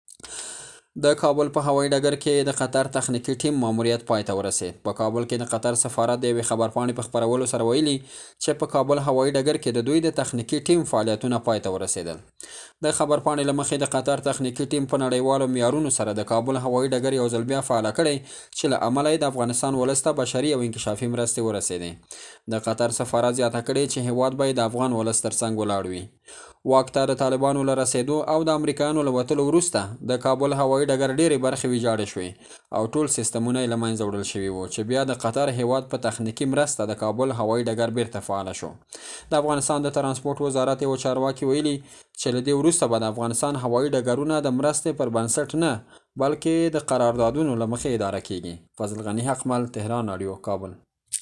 په دې اړه له کابله زمونږ دخبریال بشبړ راپور په ګډه سره آورو.